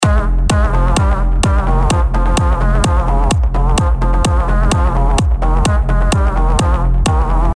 Unison / Supersaw hab ich gar nicht gebraucht, im Gegenteil es klingt damit schlechter.